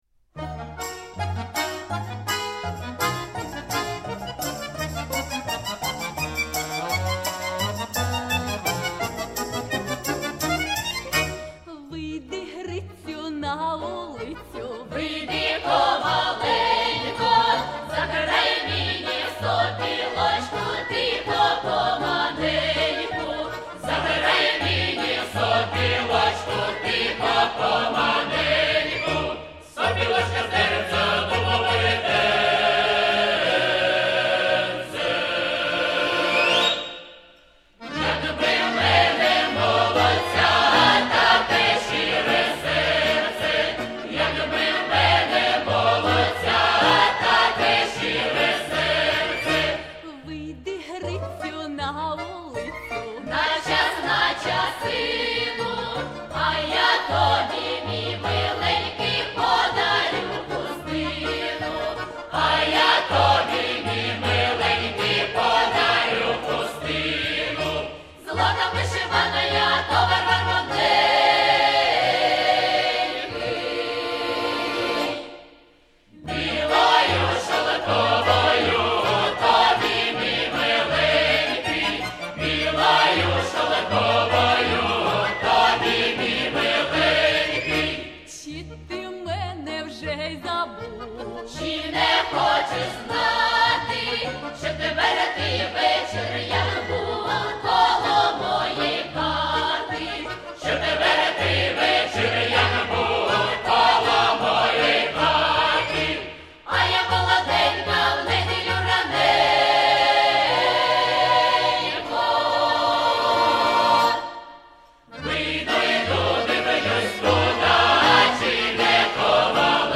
Произведения для хора
Режим: Stereo